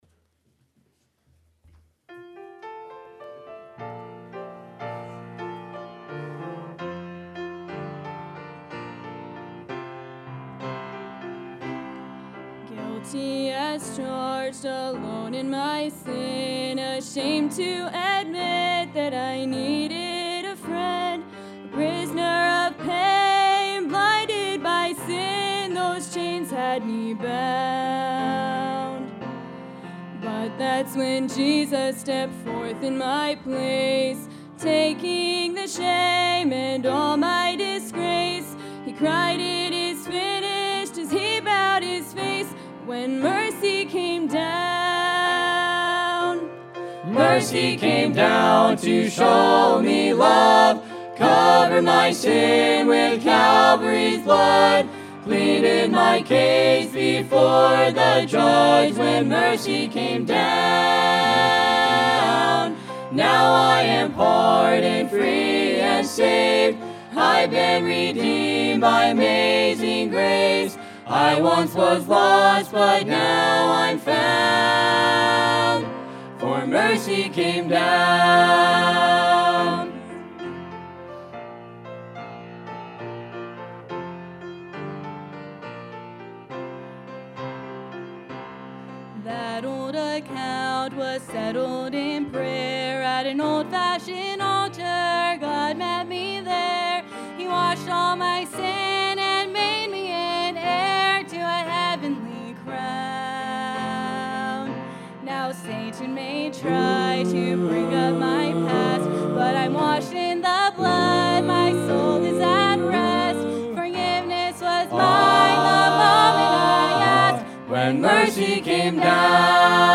Purpose of Shasta Baptist School | Sunday PM